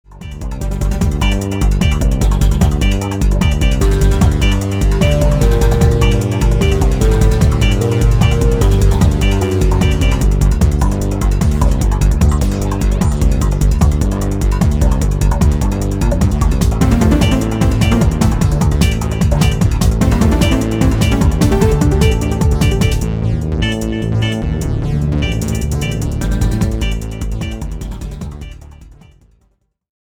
第一、第二とボスフロアＢＧＭはすごくよく似ているアレンジだったのですが
ここへ来てギャップにびびる。そういうかんじです。
それとセルフアレンジのフレーズも入っていてちょっと複雑なエレクトロになってます。